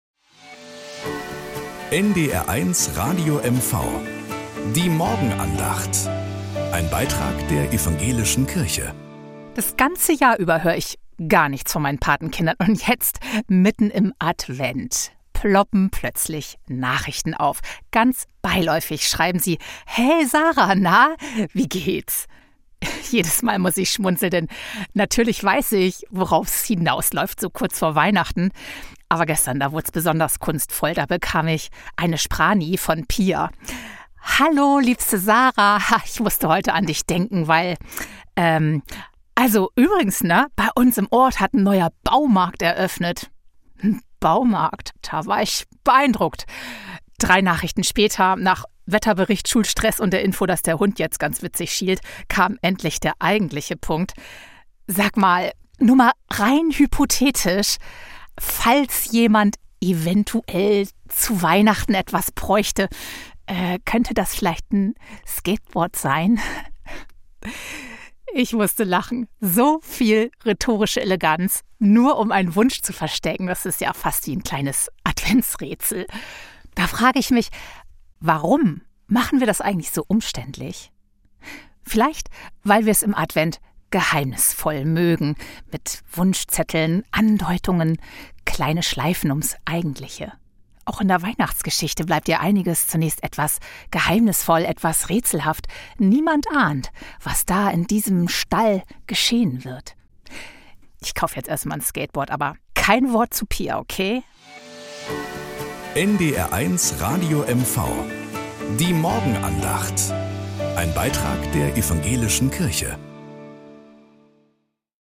Morgenandacht bei NDR 1 Radio MV
Um 6:20 Uhr gibt es in der Sendung "Der Frühstücksclub" eine